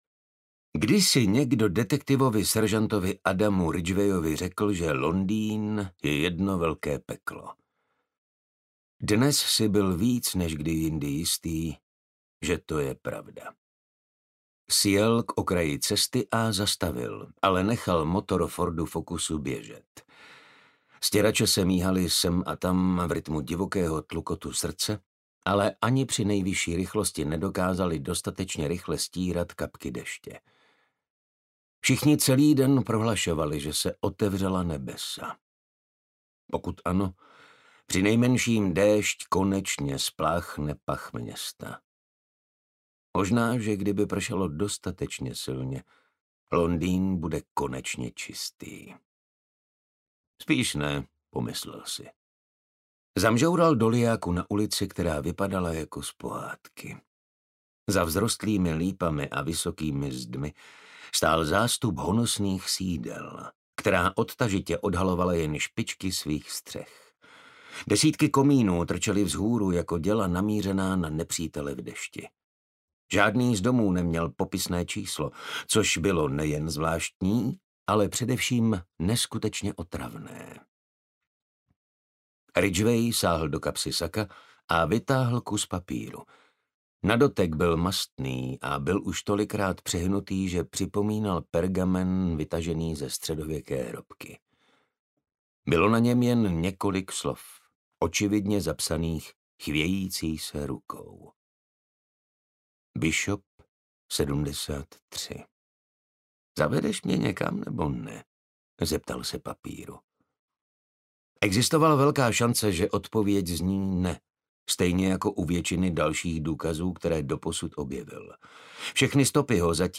Tři malá prasátka audiokniha
Ukázka z knihy
• InterpretMartin Stránský